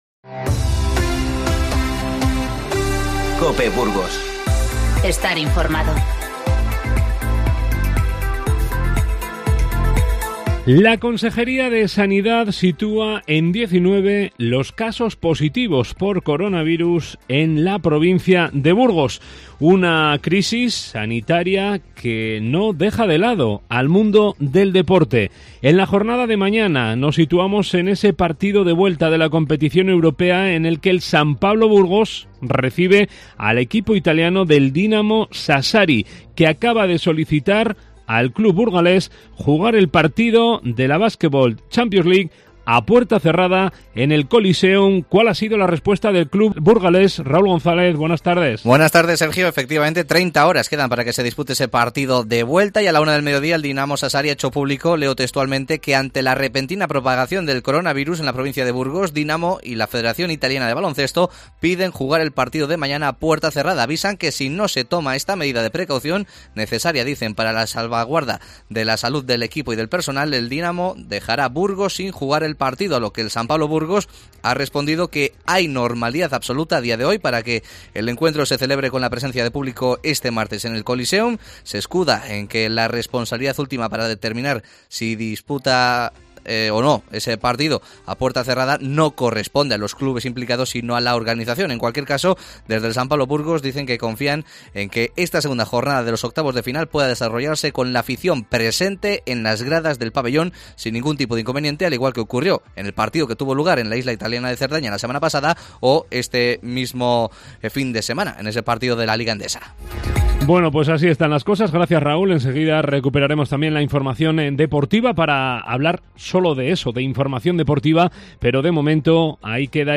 Informativo 09-03-20